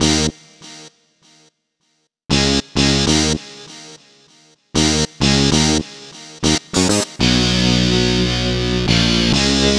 Rock Star - Power Guitar 01.wav